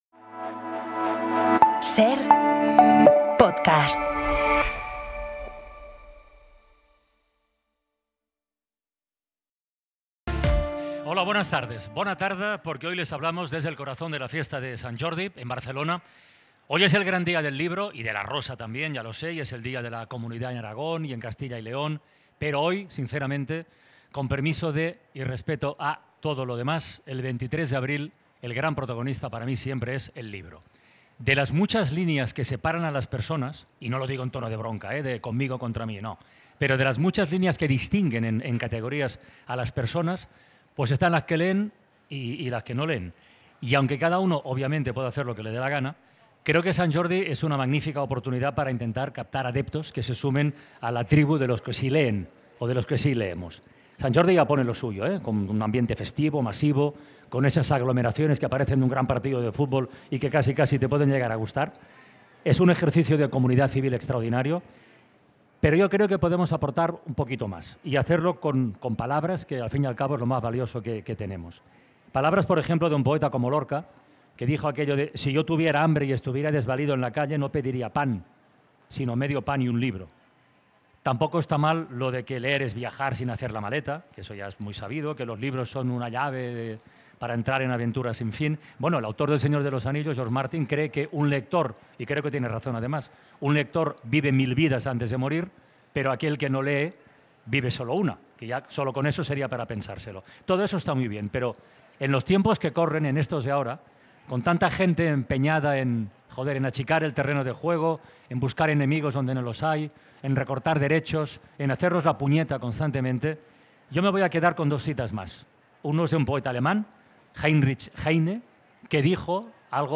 Hoy celebramos Sant Jordi en La Ventana desde Barcelona.